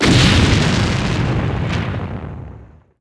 explo4.wav